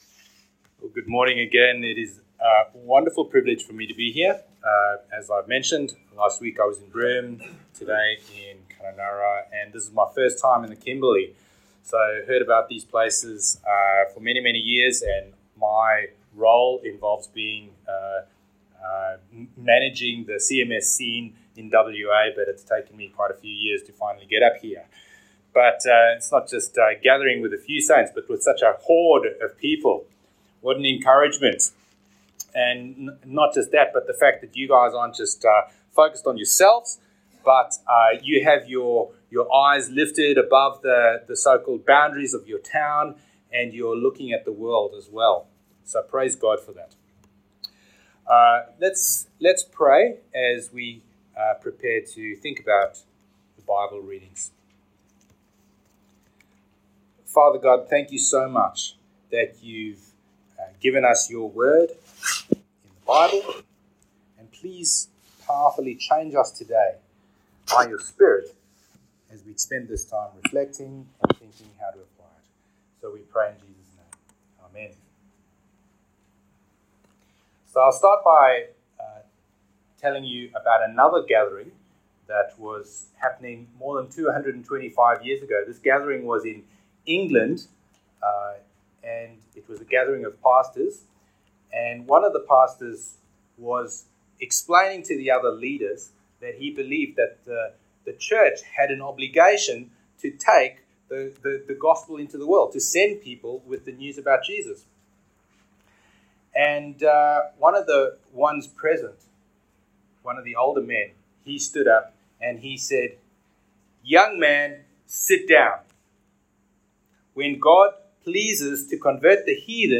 From Series: "One Off Sermons"